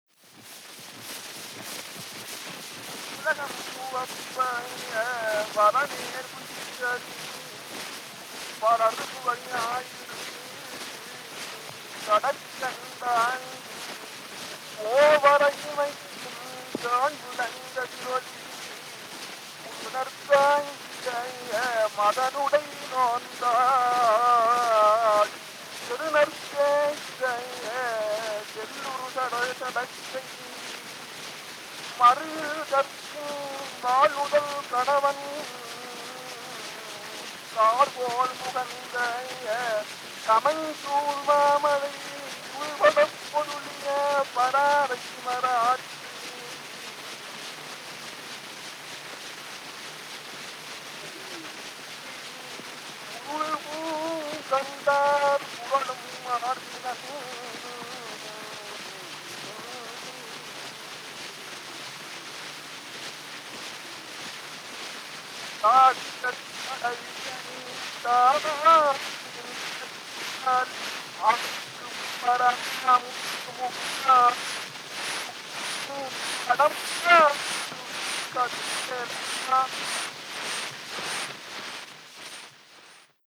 Historical sound recordings